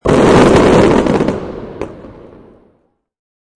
Descarga de Sonidos mp3 Gratis: explosion 21.